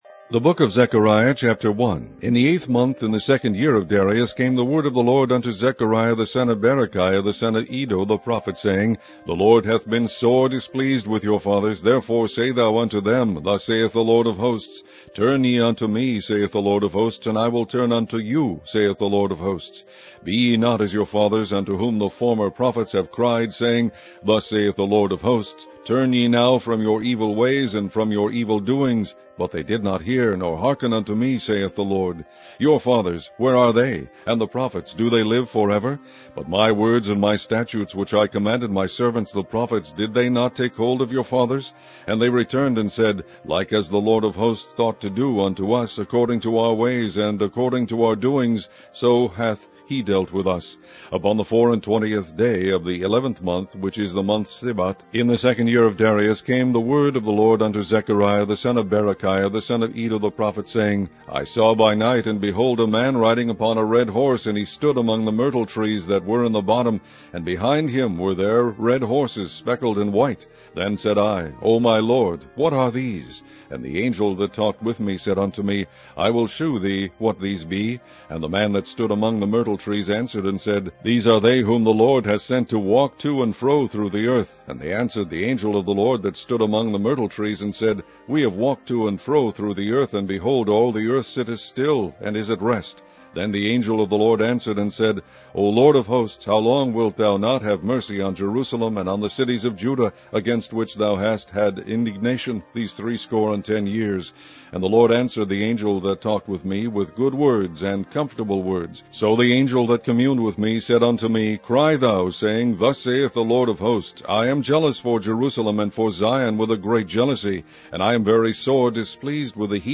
KJV MP3 Audio Bible, King James Version